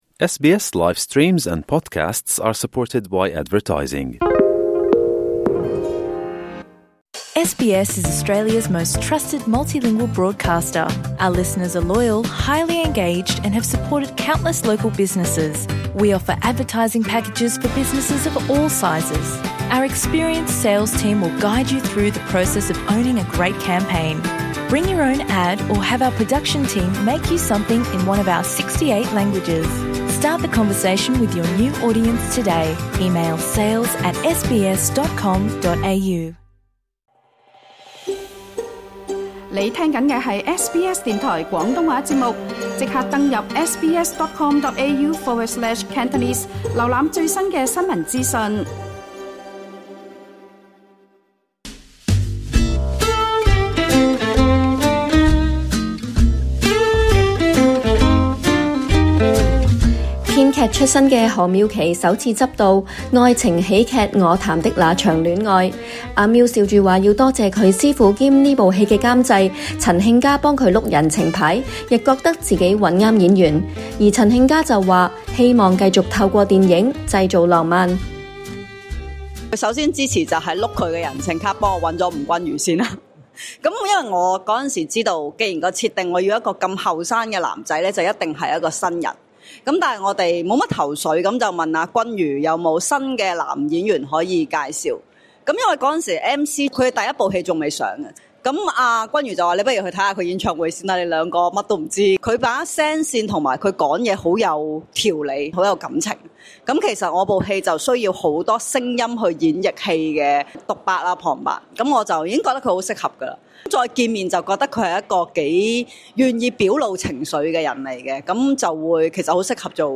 雙方合作了這麼多年，難得一起受訪有說話想跟對方說嗎？